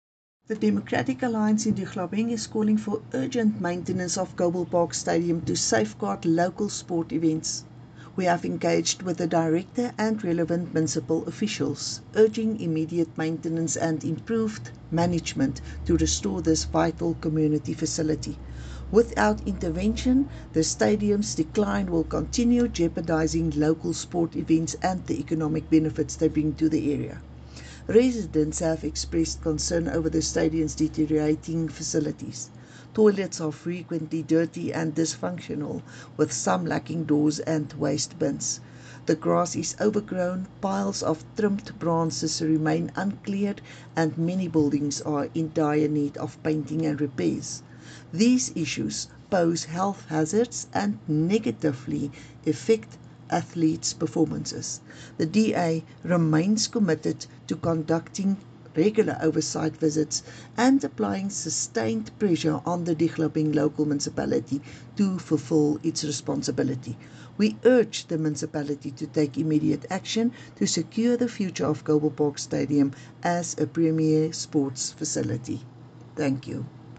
Afrikaans soundbites by Cllr Estie Senekal and